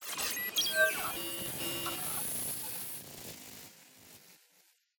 Evac_Console_Button.ogg